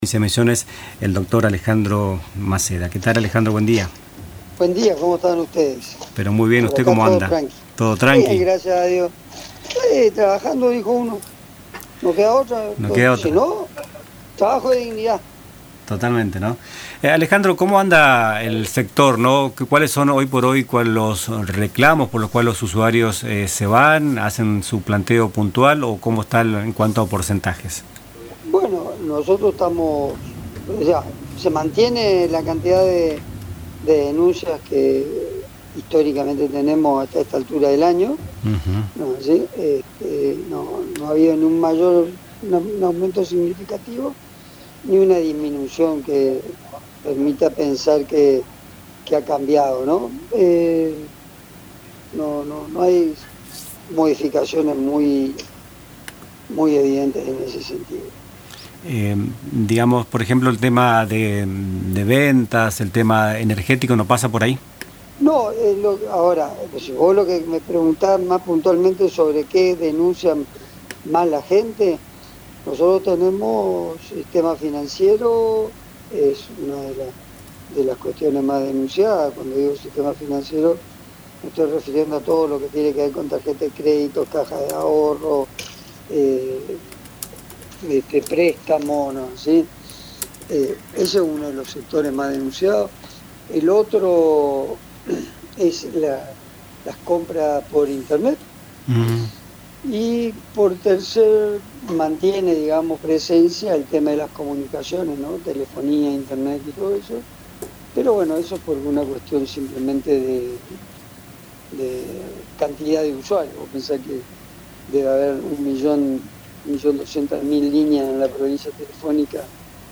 En «Nuestras Mañanas», entrevistamos al Director de Defensa del Consumidor de Misiones, Alejandro Garzón Maceda, que abordó las denuncias que reciben a diario, destacando que aún persisten sobre comercios en general, pero también sobre energía, lo cual hace dos años cambió la historia, indicó. Resalta que los usuarios deben realizar las denuncias correspondientes cuando hay estafas o compras no realizadas.